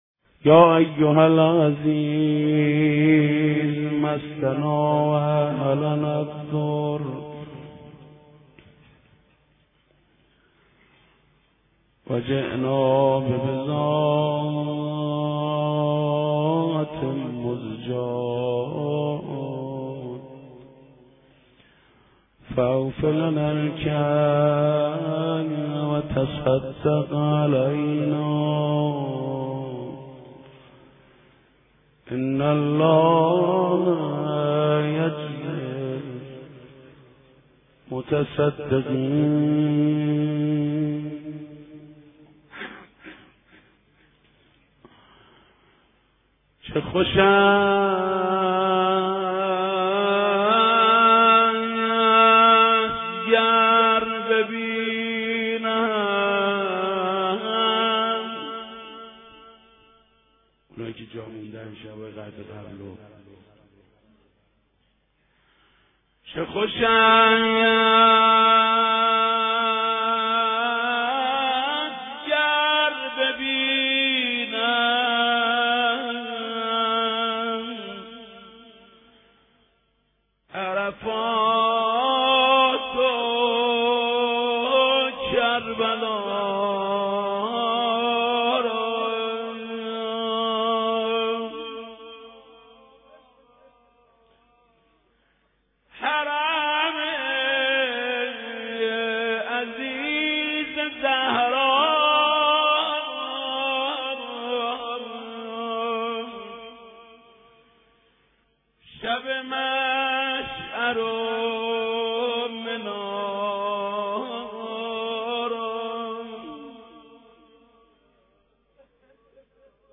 مناجات با امام زمان (علیه السلام) (۳)
قاری : حاج محمود کریمی